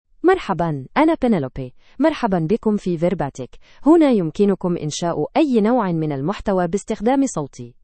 Penelope — Female Arabic (Standard) AI Voice | TTS, Voice Cloning & Video | Verbatik AI
PenelopeFemale Arabic AI voice
Voice sample
Listen to Penelope's female Arabic voice.
Penelope delivers clear pronunciation with authentic Standard Arabic intonation, making your content sound professionally produced.